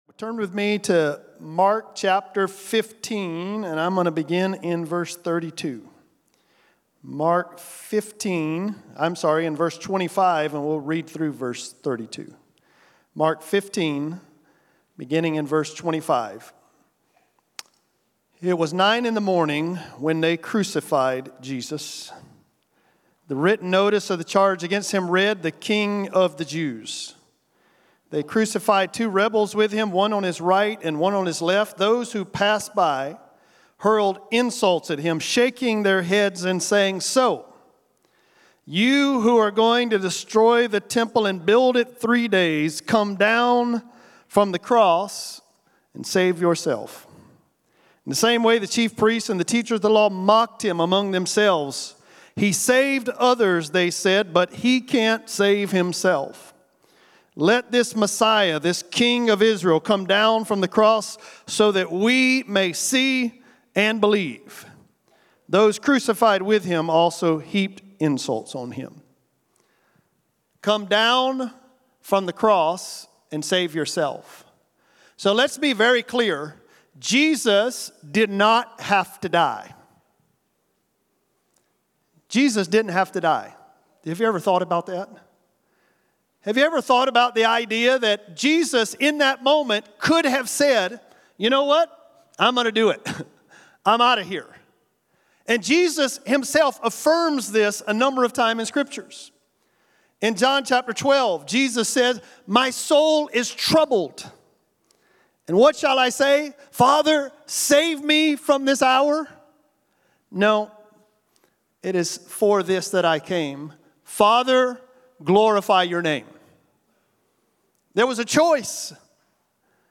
Message
at Family Camp, the evening of July 5